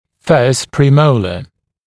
[fɜːst prɪ’məulə][фё:ст при’моулэ]первый премоляр